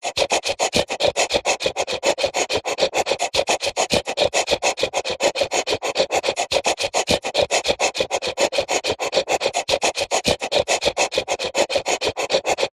دانلود صدای نفس نفس زدن مداوم سگ بزرگ و ترسناک از ساعد نیوز با لینک مستقیم و کیفیت بالا
جلوه های صوتی